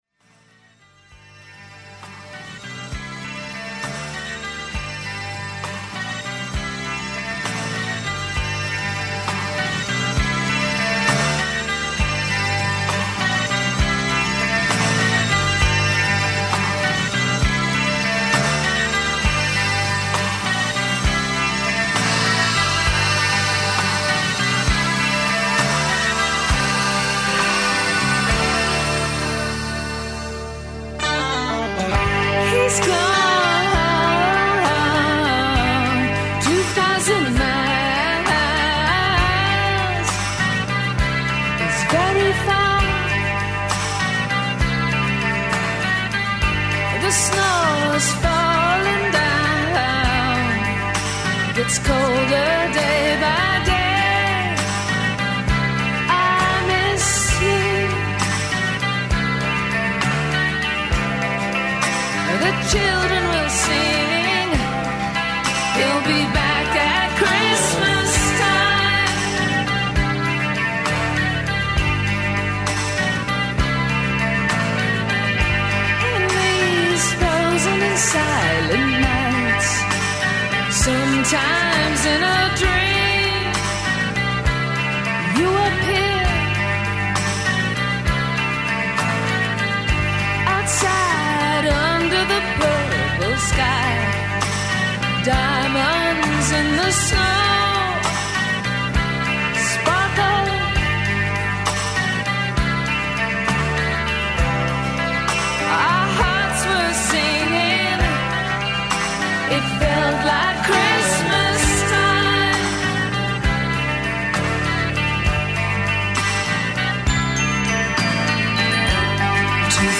Гости емисије су и чланови састава Хаџи продане душе. Рок састав Хаџи продане душе основан је 2009. године, тренутно напорно раде и за наредну годину спремају свој први албум.